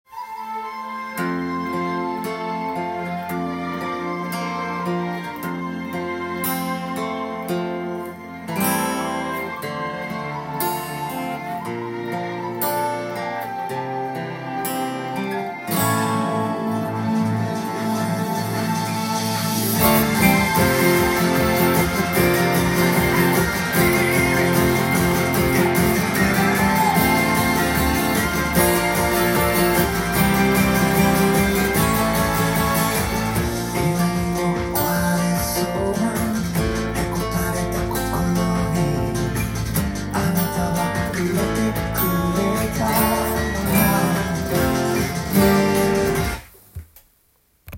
アコギTAB譜
音源に合わせて譜面通り弾いてみました
アコースティックギターでアルペジオから始まりますので
ドラムの後にコードストロークが始まりAメロに突入しますが